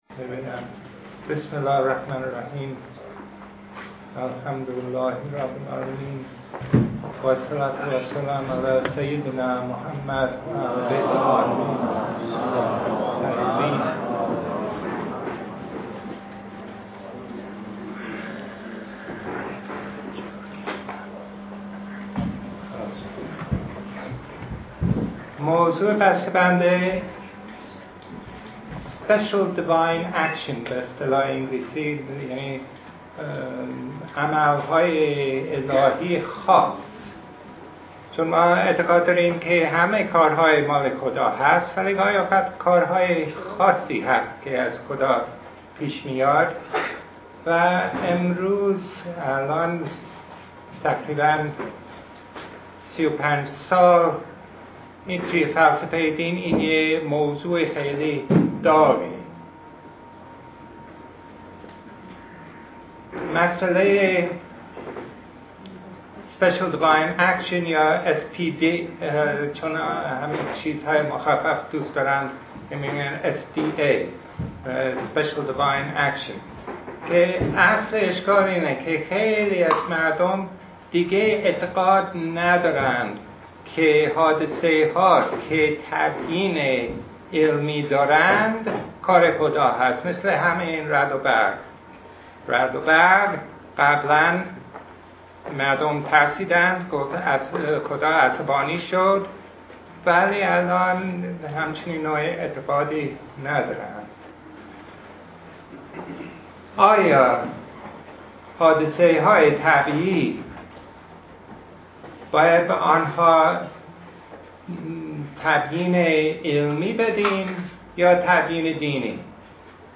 سخنرانی
نشست علمی